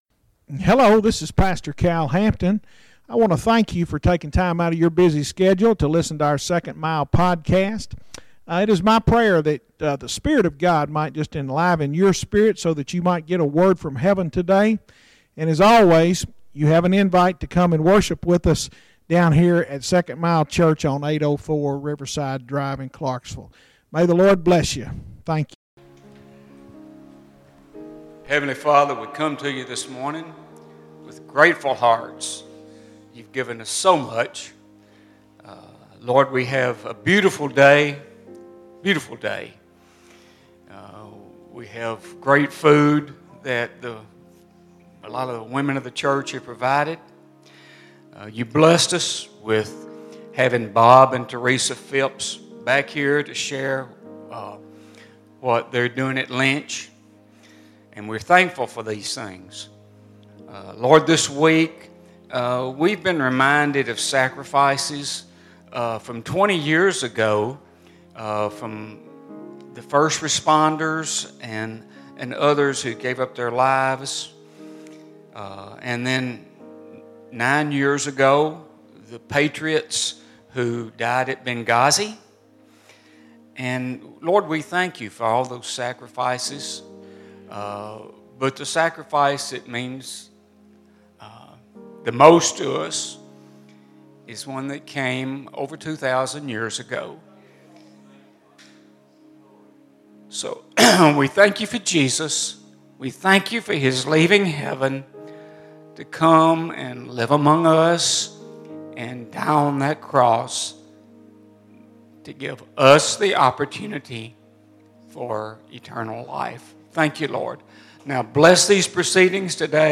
Sermons Archive - 2nd Mile Church